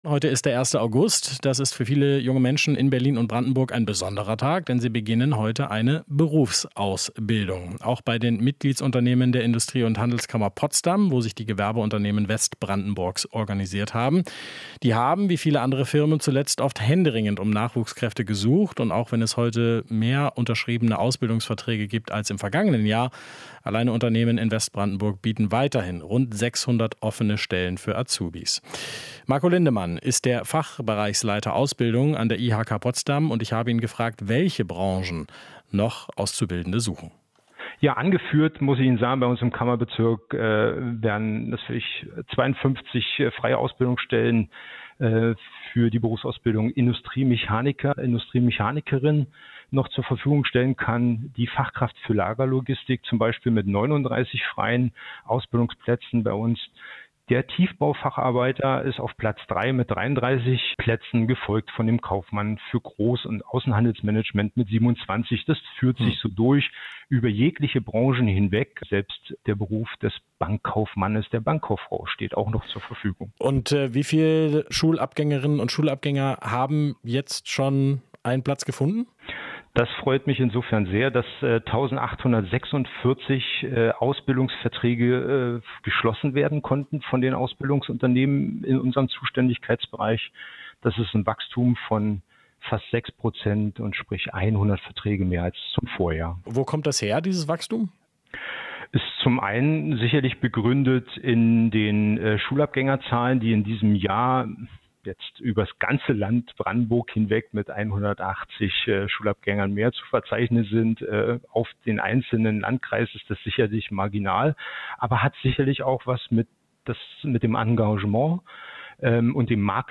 Interview - Noch viele freie Lehrstellen in Brandenburg